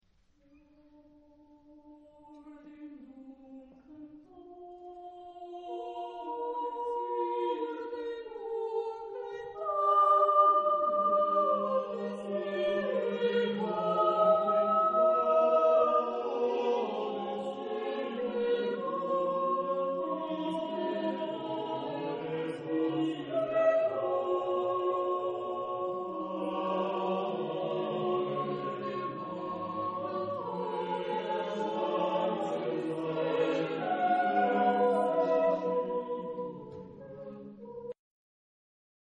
Genre-Style-Forme : contemporain ; Madrigal ; Profane
Caractère de la pièce : fugué ; lent
Type de choeur : SSATB  (5 voix mixtes )